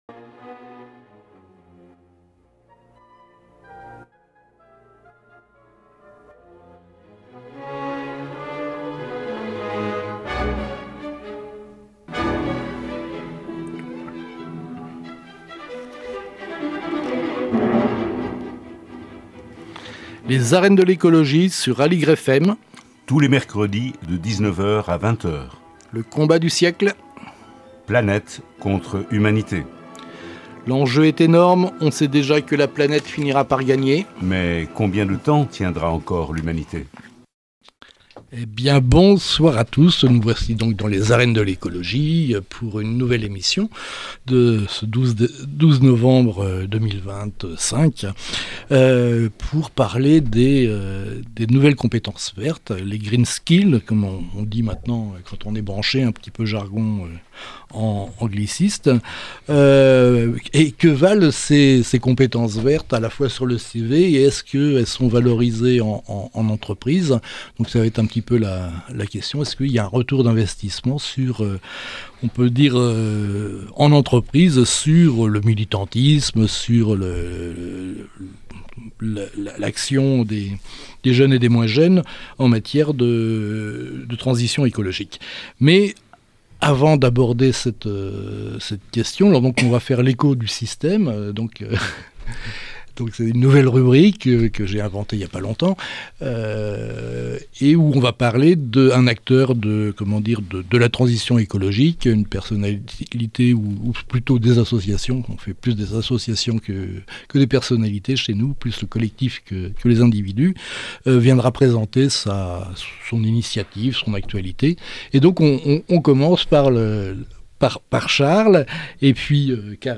Nos deux invitées ont, depuis leurs positions d'observation sur le monde du travail, beaucoup à nous dire sur la question.